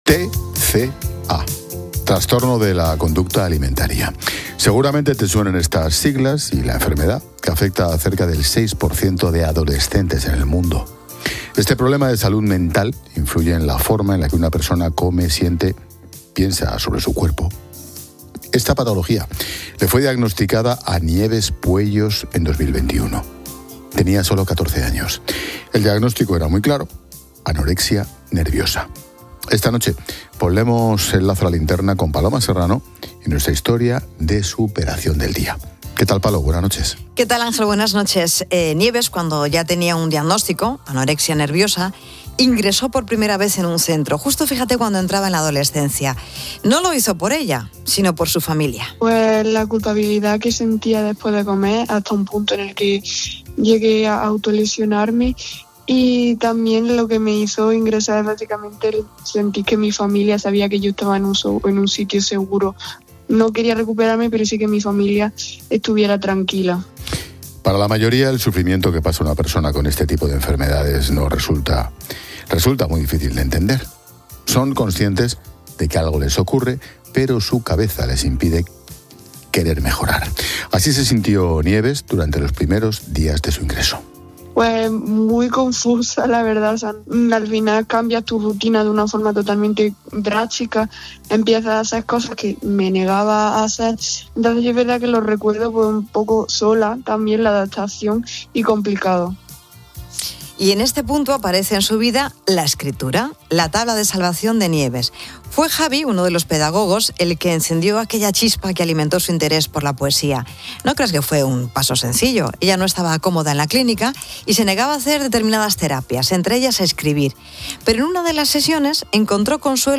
La joven narra en 'La Linterna' de COPE su historia de superación contra la anorexia, un camino donde la poesía se convirtió en su principal tabla de salvación